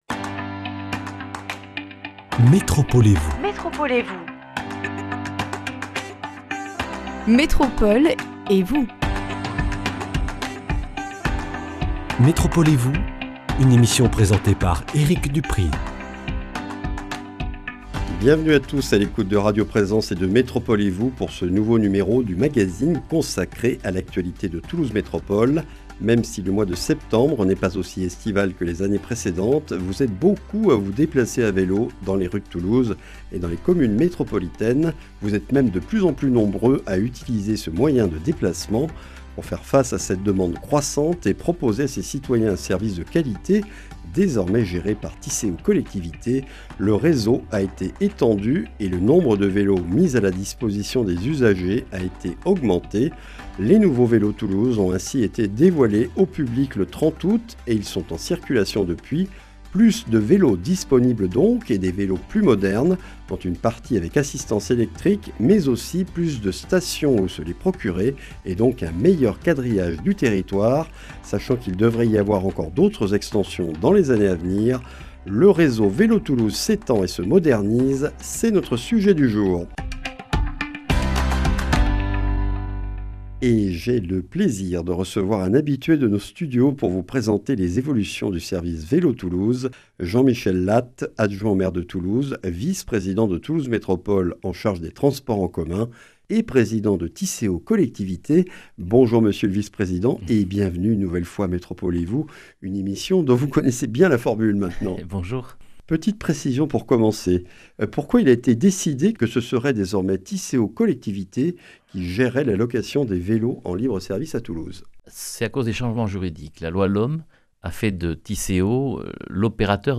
Un réseau étendu et modernisé présenté par Jean-Michel Lattes, adjoint au maire de Toulouse, vice-président de Toulouse Métropole chargé des transports en commun, président de Tisséo Collectivités.